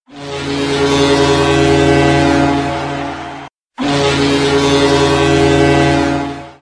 sv-werder-bremen-nebelhorn-als-mp3-klingelton.mp3